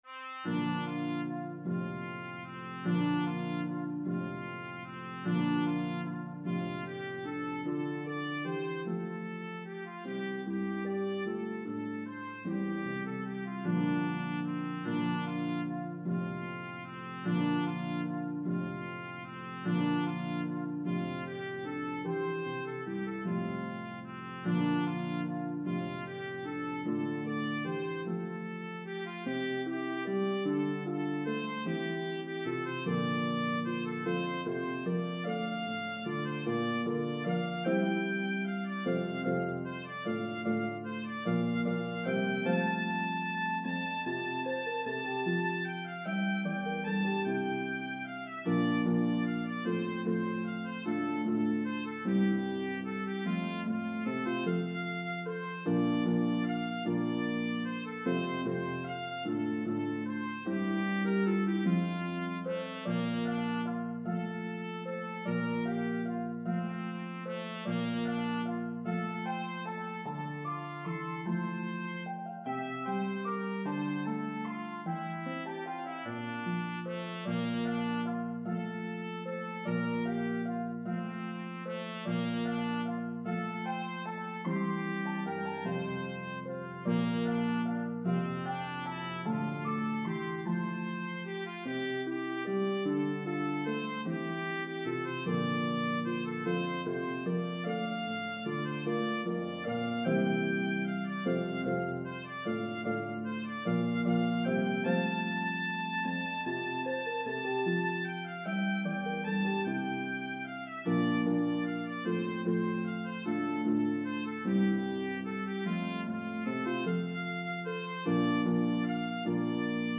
Harp and Trumpet in B-flat version